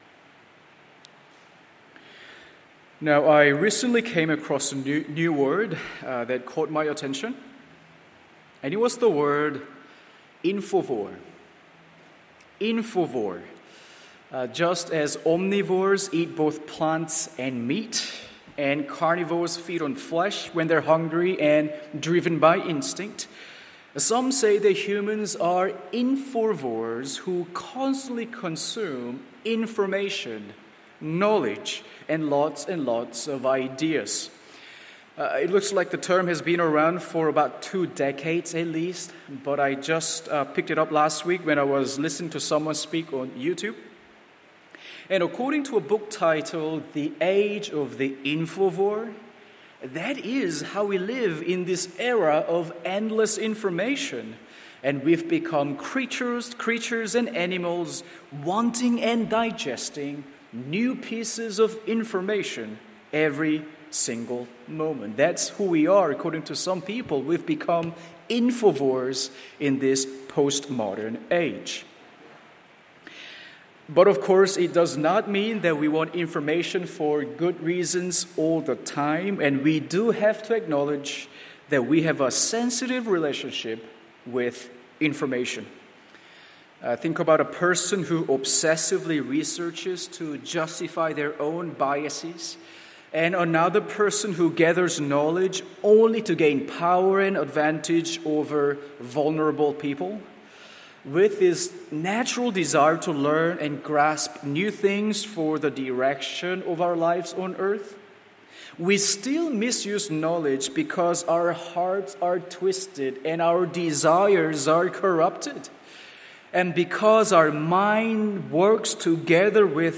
Morning Service Luke 20:1-19…